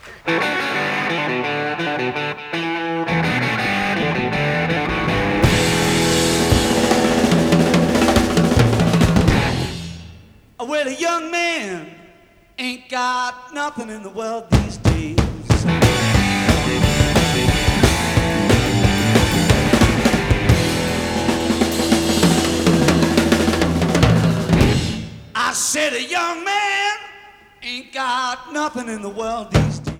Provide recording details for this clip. Everything is upfront and crystal clear.